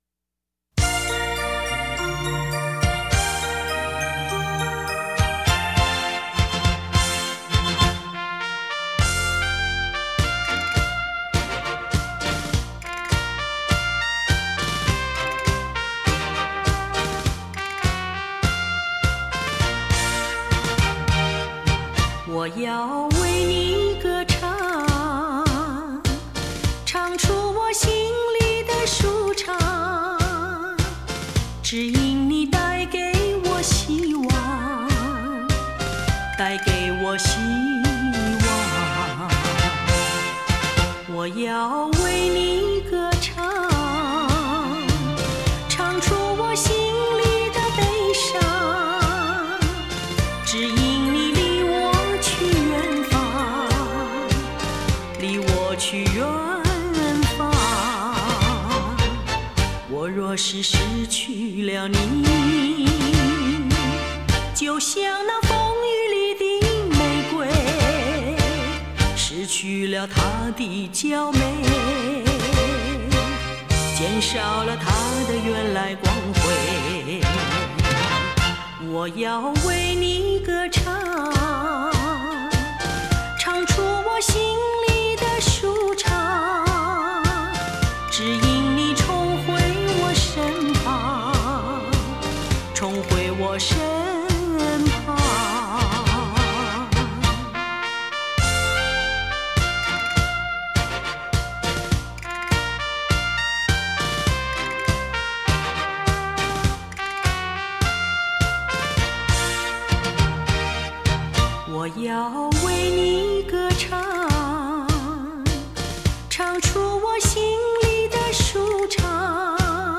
撇开知名度不谈，就以她的演唱功力而言，她的音域广、气长、音准、擅长控制音量，富感情。